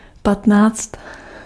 Ääntäminen
Ääntäminen France Tuntematon aksentti: IPA: /kɛ̃z/ Haettu sana löytyi näillä lähdekielillä: ranska Käännös Ääninäyte Substantiivit 1. patnáct Suku: m .